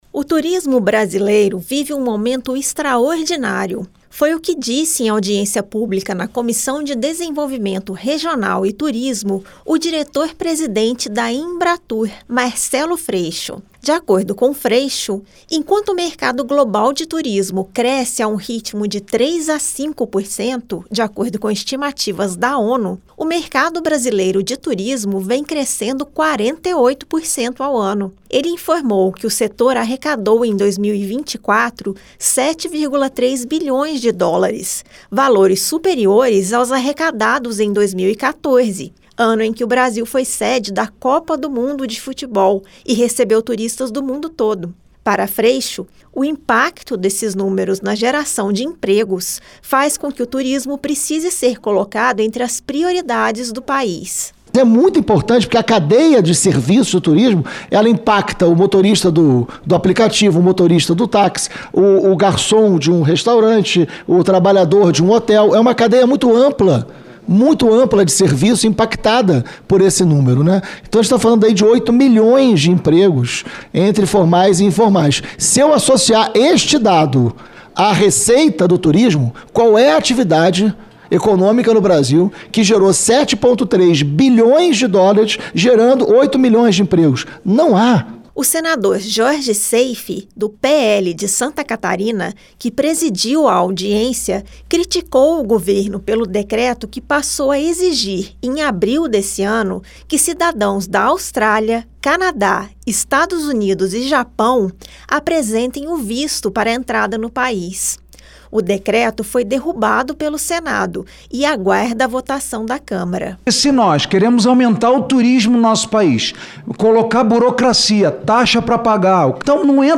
O diretor-presidente da Embratur, Marcelo Freixo, afirmou nesta terça-feira (19), durante audiência na Comissão de Desenvolvimento Regional e Turismo (CDR), que o setor de turismo vive um momento extraordinário no Brasil. Segundo Freixo, enquanto o mercado global cresce de 3% a 5% ao ano, o país tem registrado um crescimento de 48%, com uma arrecadação superior à de 2014, ano em que o Brasil sediou a Copa do Mundo.